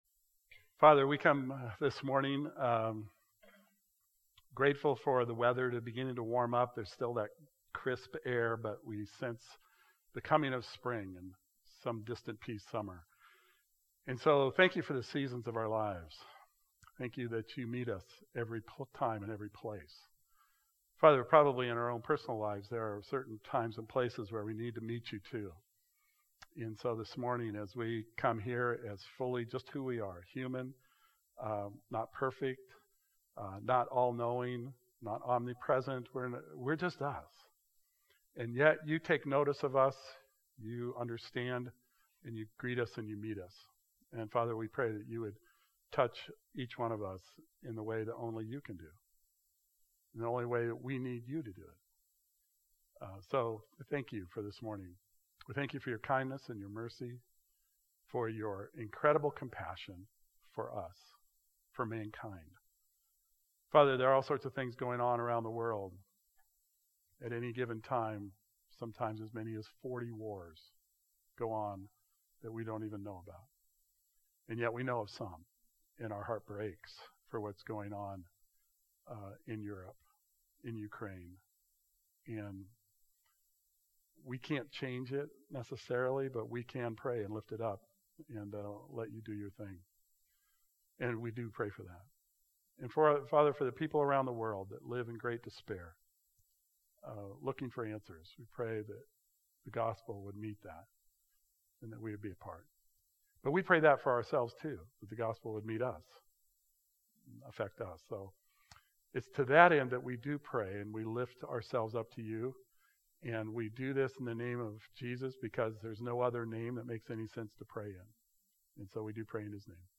Today finishes the sermon series, Matthew: Kingdom Secrets.